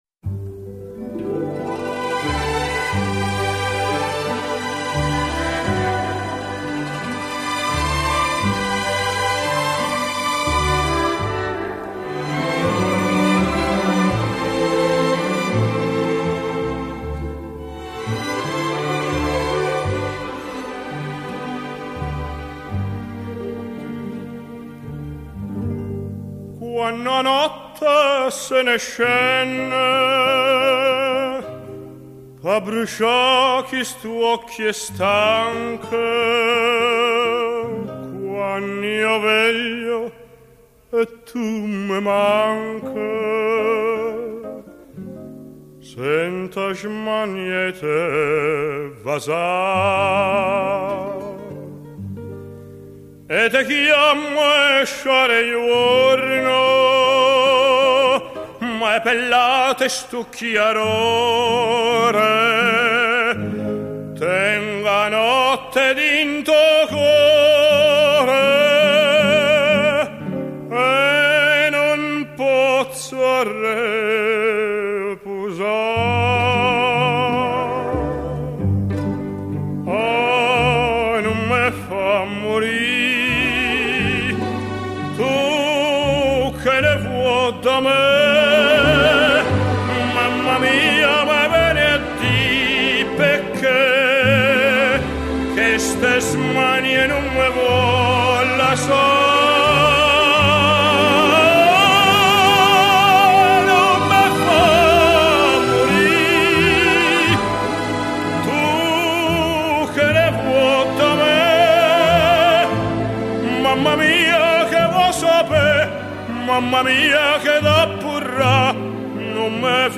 浪漫意大利歌曲
由于原录音是1962